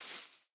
1.21.4 / assets / minecraft / sounds / step / grass6.ogg
grass6.ogg